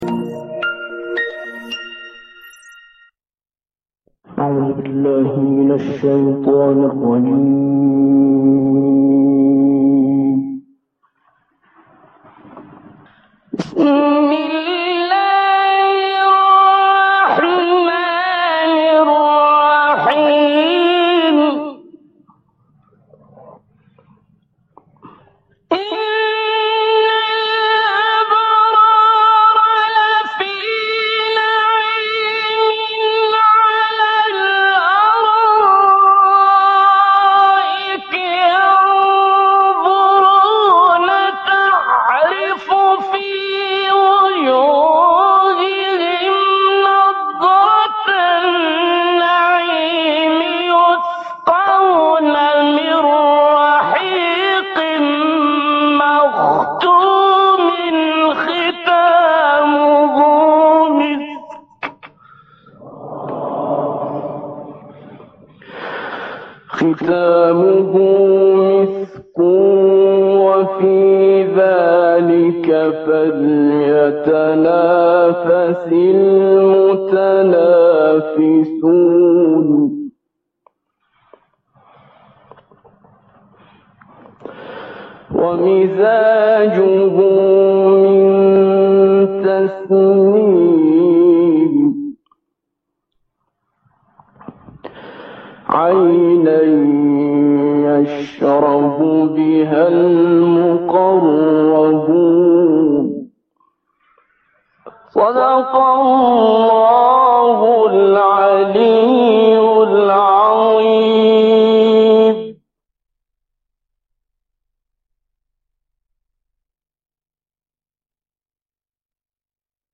مجمع عمومی فوق العاده شرکت توسعه فن افزار توسن - نماد: فن‌افزار